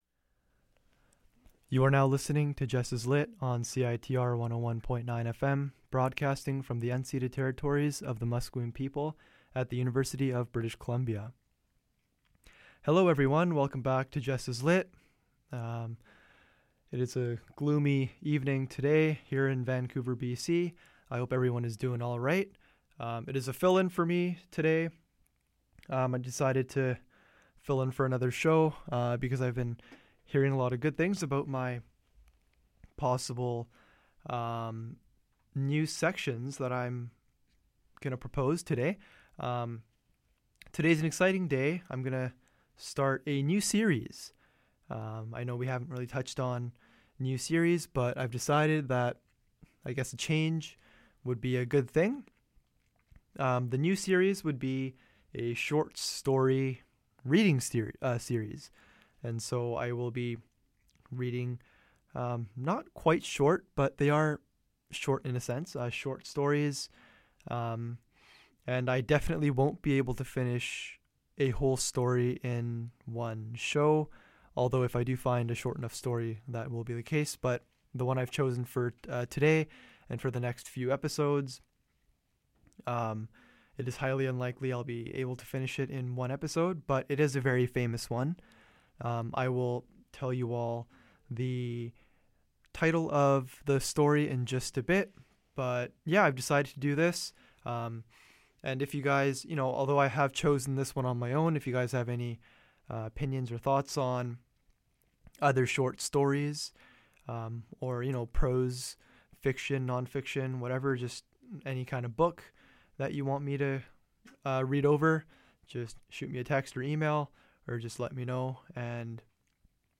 The story is called "The Metamorphosis" by Franz Kafka. Come join me in reading one of Kafka's most famous works!